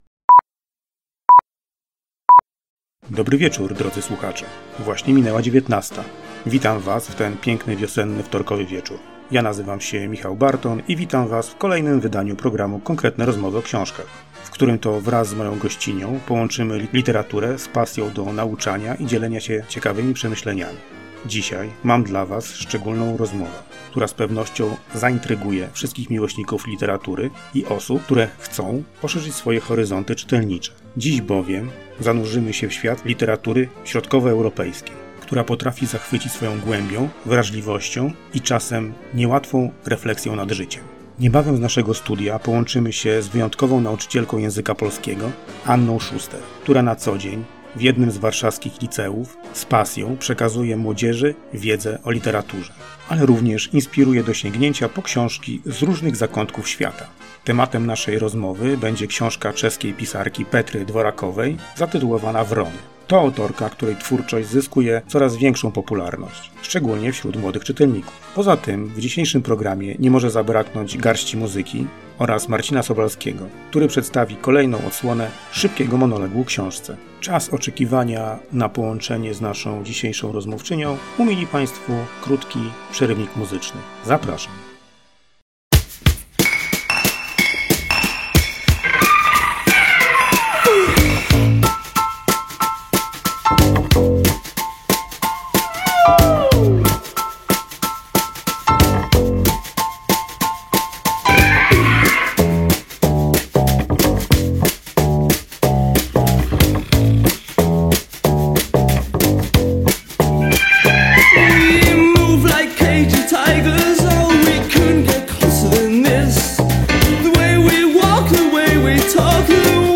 Dzisiaj mam dla Was szczególną rozmowę, która z pewnością zaintryguje wszystkich miłośników literatury i osób, które chcą poszerzyć swoje horyzonty czytelnicze. Dziś bowiem zanurzymy się w świat literatury środkowoeuropejskiej, która potrafi zachwycić swoją głębią, wrażliwością i… czasem niełatwą refleksją nad życiem.